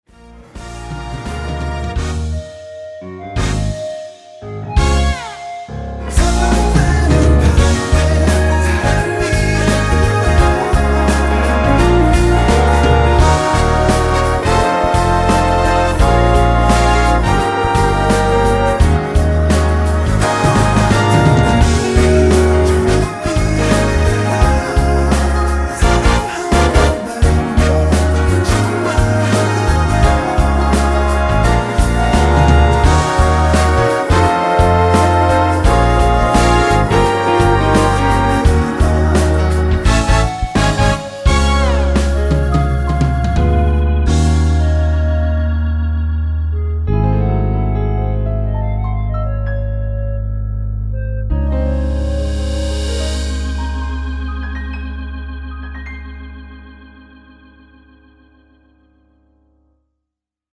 2분56초 부터 10초 정도 보컬 더블링 된 부분은 없습니다.(미리듣기 확인)
원키에서(-1)내린 멜로디와 코러스 포함된 MR입니다.
앞부분30초, 뒷부분30초씩 편집해서 올려 드리고 있습니다.
중간에 음이 끈어지고 다시 나오는 이유는